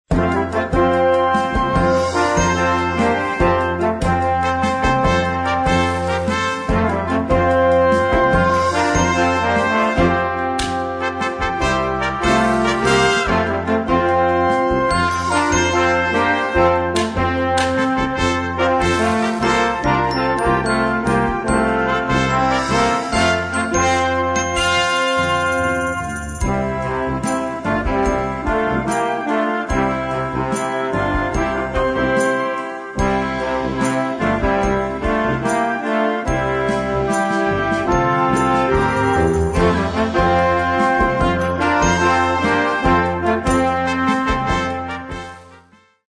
Une pièce de concert stimulante pour un jeune groupe